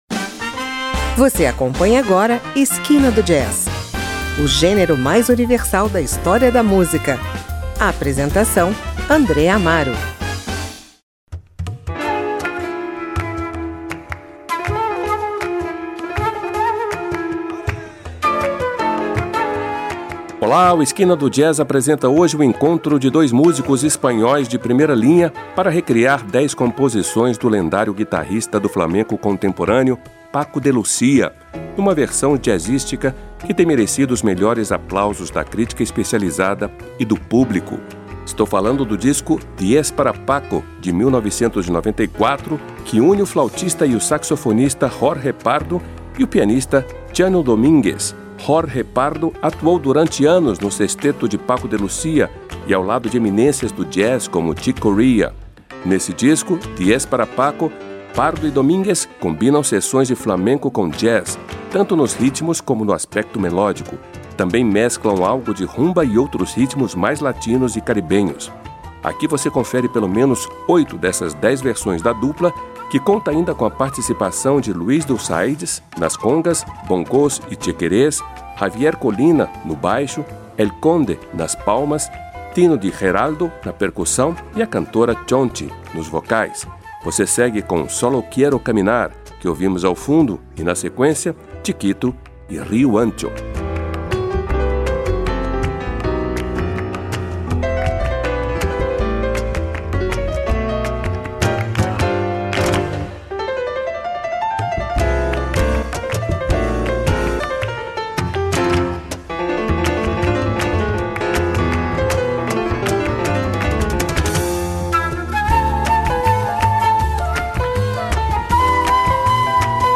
pianista
jazz espanhol